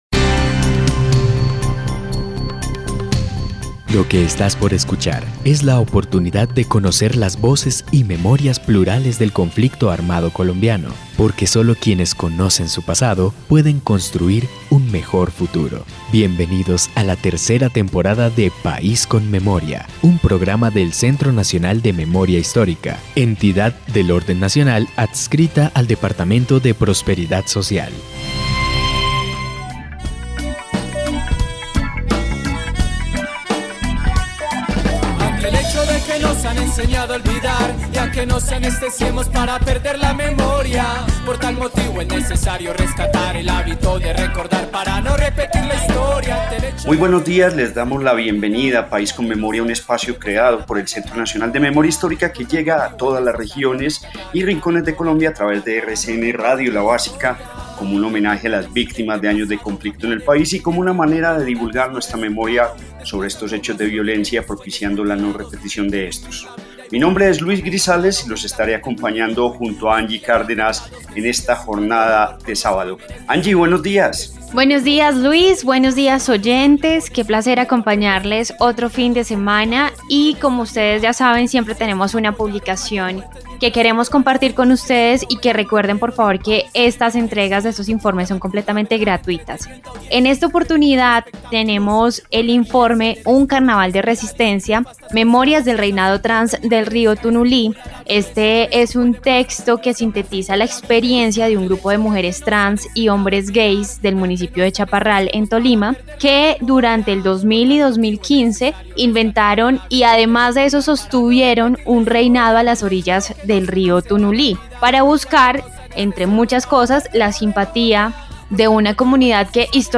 Descripción (dcterms:description) Capítulo número 23 de la tercera temporada de la serie radial "País con Memoria". Conversacional sobre la producción analítica que tiene el Observatorio como el portal de datos, infografías, estudios, boletines informativos, entre otros productos.